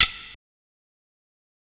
Godzilla Perc 5.wav